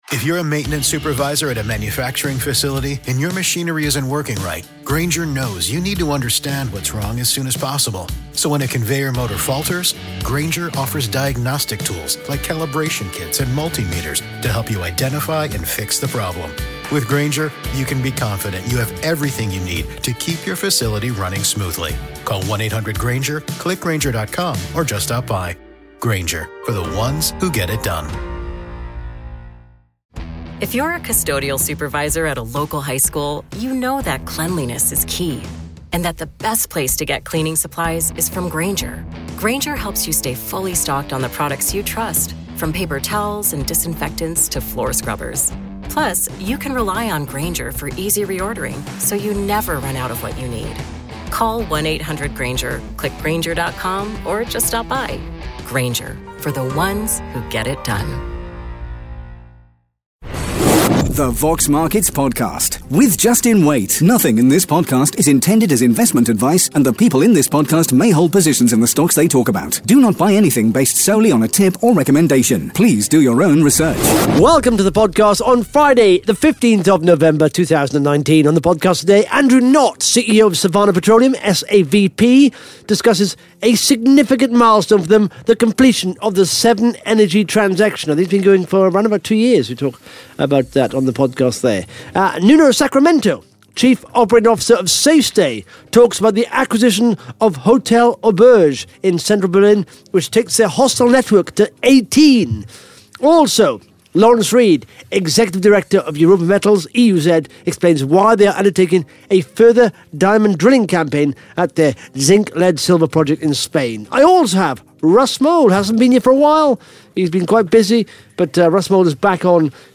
(Interview starts at 10 minutes 54 seconds)